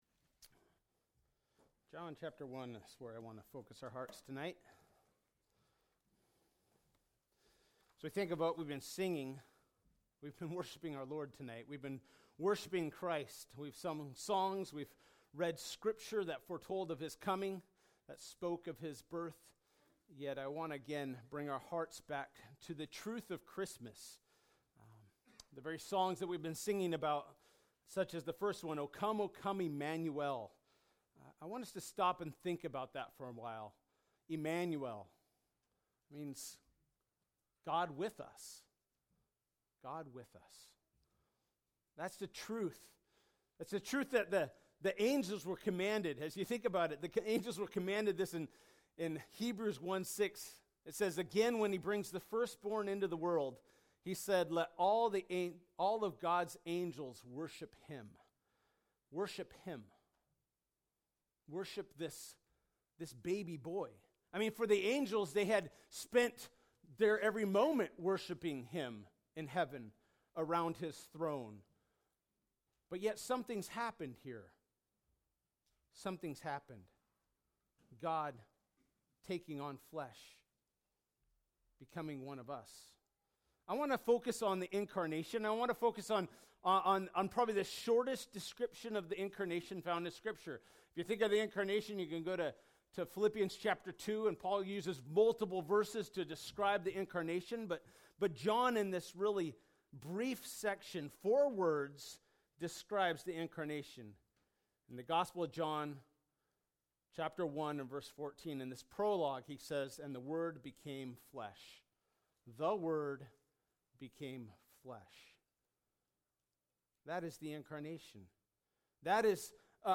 1 John – Christmas Eve service